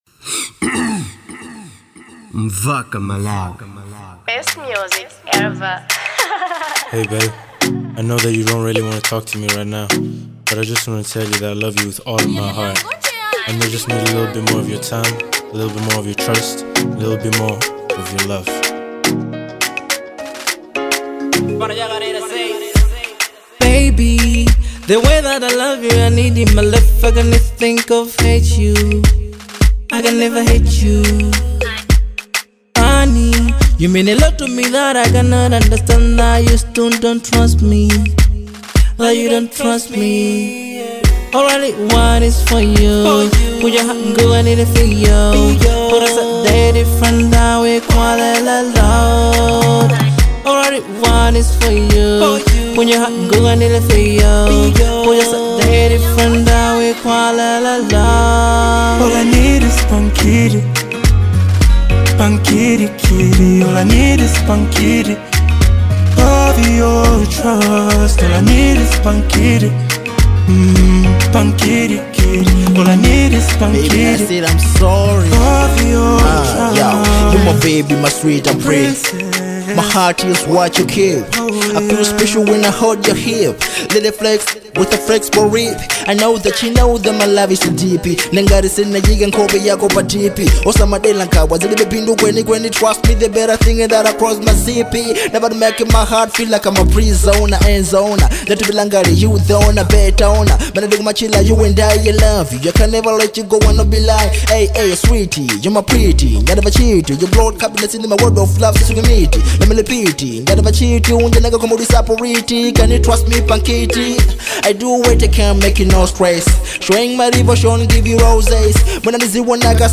type:love song